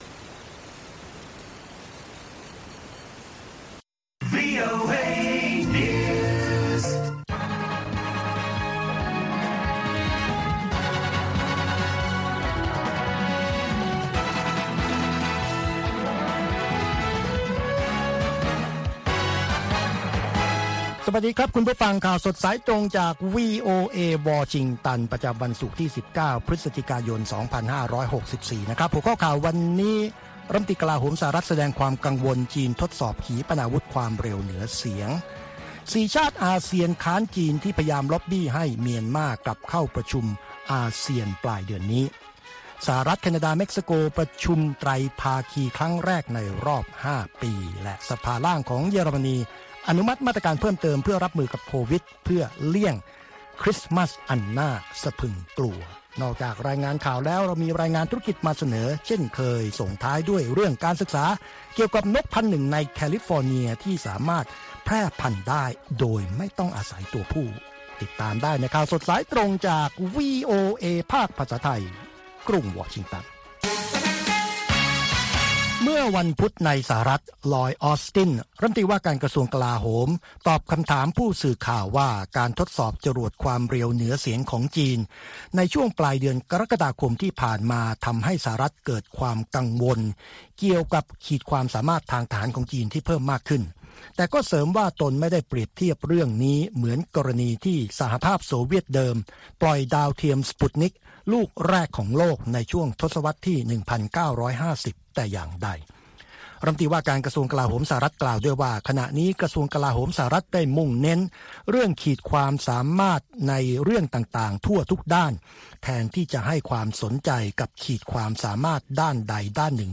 ข่าวสดสายตรงจากวีโอเอ ภาคภาษาไทย 6:30 – 7:00 น. ประจำวันศุกร์ที่ 19 พฤศจิกายน ตามเวลาในประเทศไทย